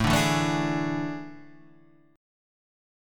AM7sus4 chord {5 5 2 2 3 4} chord